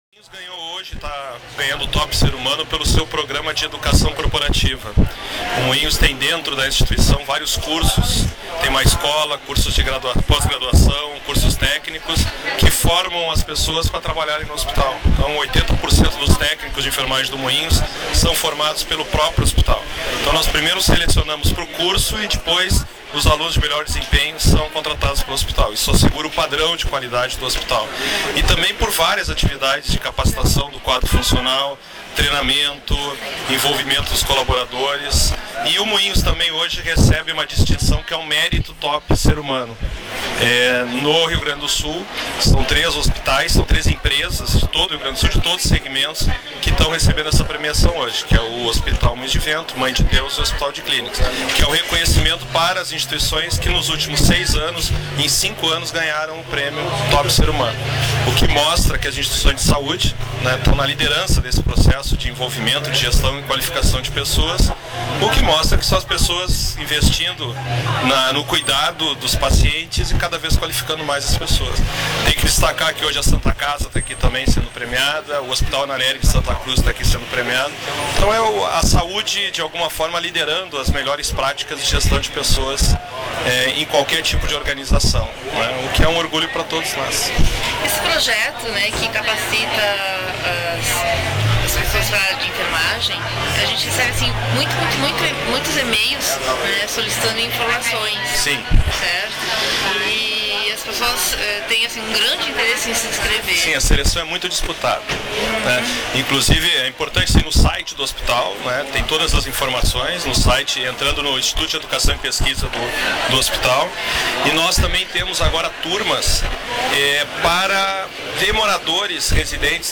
Confira alguns depoimentos: